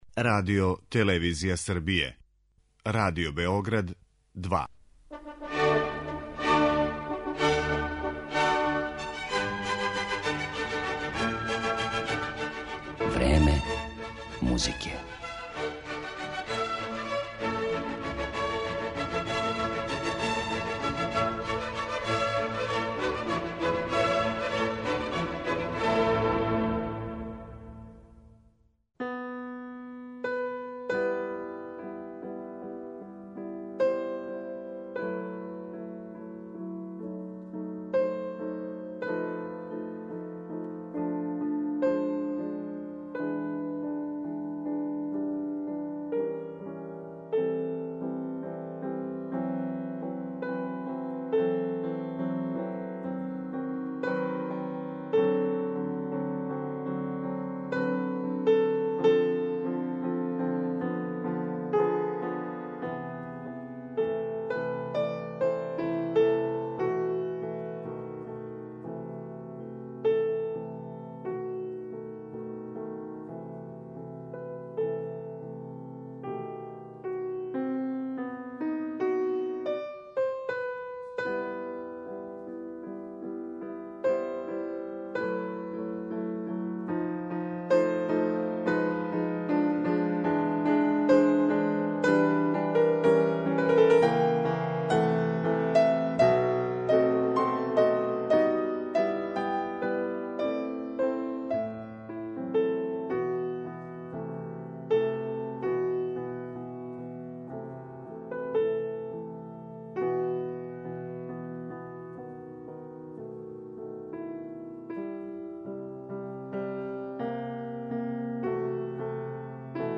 Уз делове интервјуа који нам открива њен однос према музици, интерпетацији, одређеним композиторима, педагогији, оригиналности међу извођачима и другим сегменитма бављења музиком, у извођењу ове пијанисткиње чућете композиције Листа, Дебисија, Равела, Шумана, али и домаћих аутора међу којима су Василије Мокрањац и Срђан Хофман.